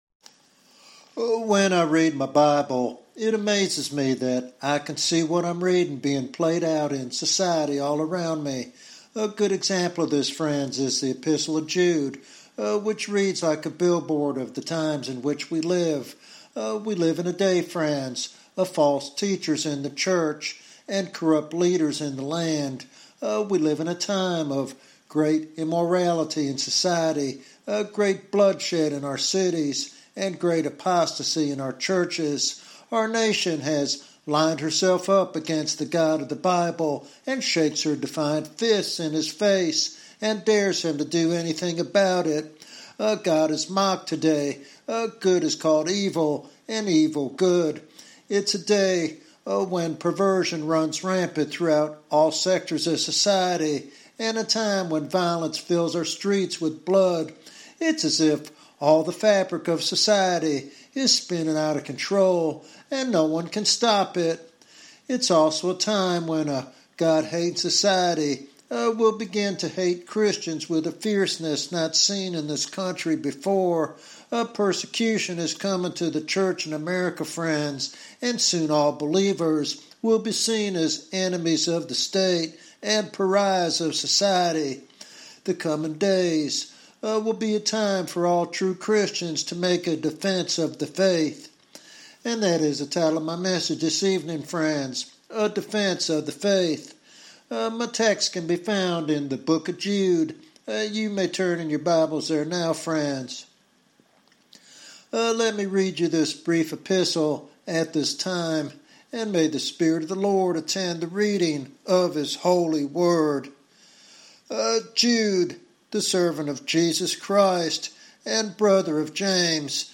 This sermon is a clarion call for believers to remain steadfast and courageous in a culture increasingly hostile to Christianity.